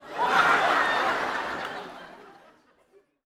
Audience Laughing-07.wav